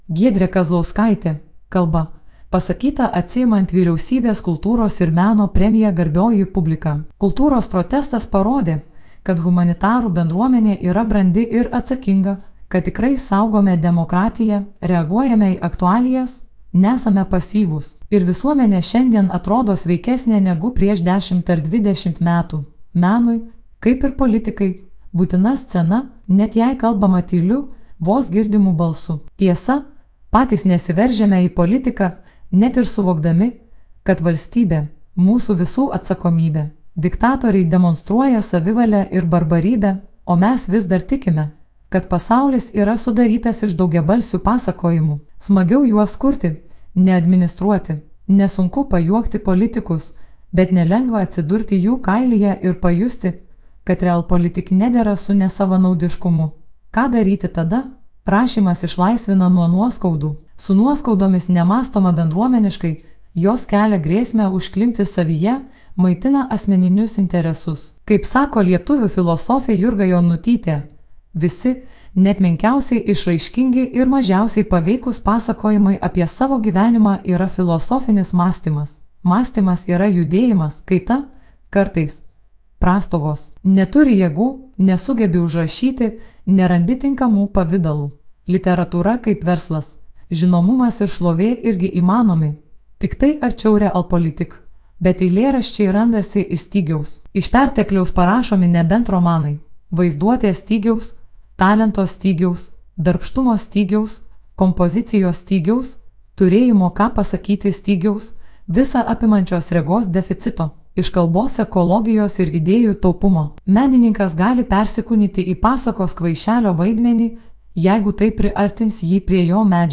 kalba
Kalba, pasakyta atsiimant Vyriausybės kultūros ir meno premiją